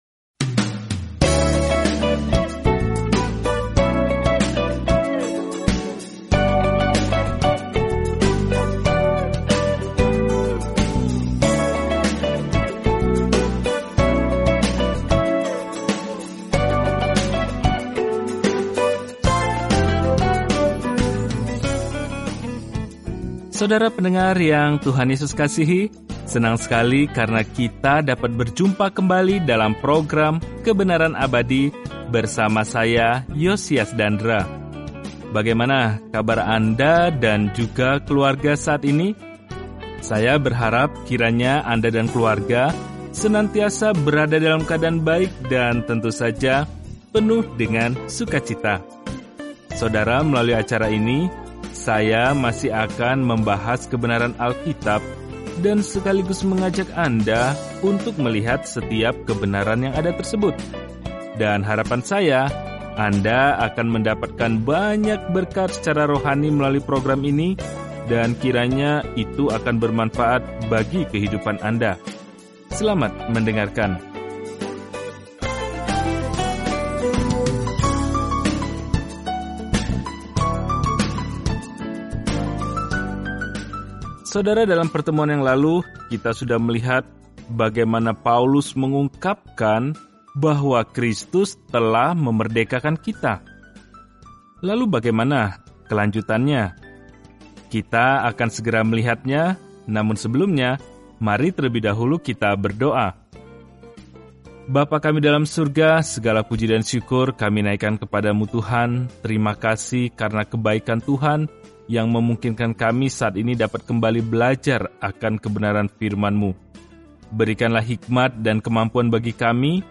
Firman Tuhan, Alkitab Galatia 5:1-4 Hari 13 Mulai Rencana ini Hari 15 Tentang Rencana ini “Hanya melalui iman” kita diselamatkan, bukan melalui apa pun yang kita lakukan untuk layak menerima anugerah keselamatan – itulah pesan yang jelas dan langsung dari surat kepada jemaat Galatia. Jelajahi Galatia setiap hari sambil mendengarkan pelajaran audio dan membaca ayat-ayat tertentu dari firman Tuhan.